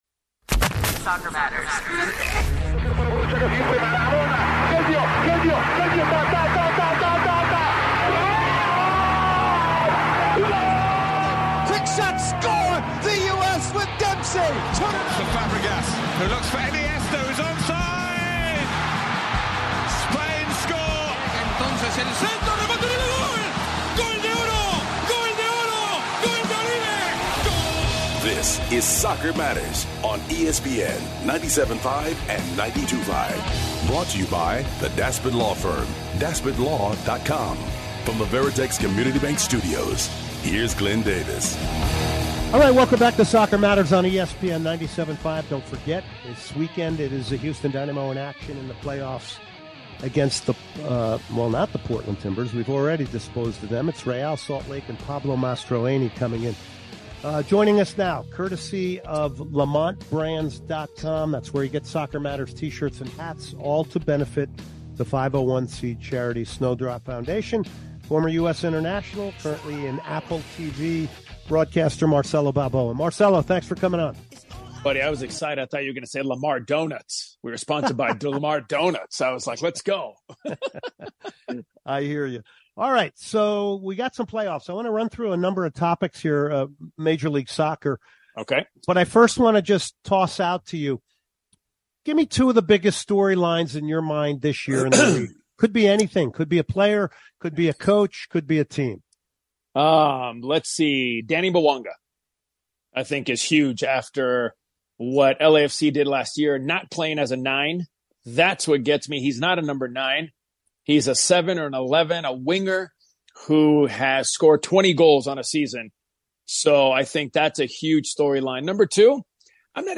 Interviews are all things MLS from different perspectives.